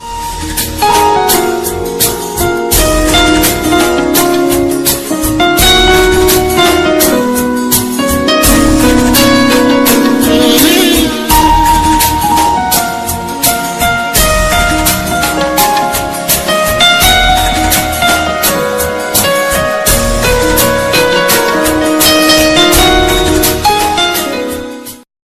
Category: Instrumental Ringtones